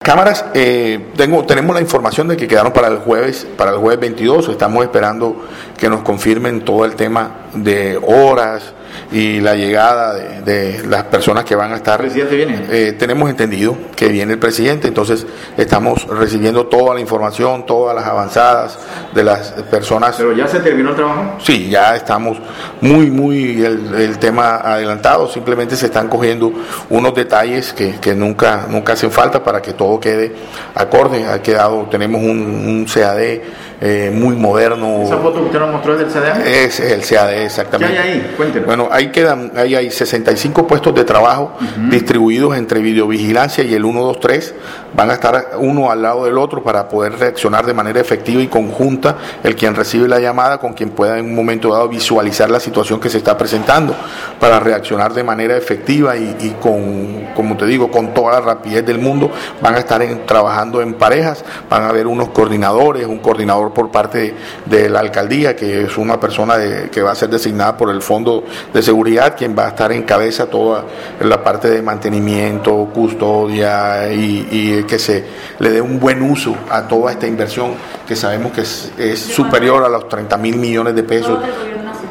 Así lo  manifestó  el  director de la  Oficina de Seguridad  y Convivencia del distrito, Yesid Turbay.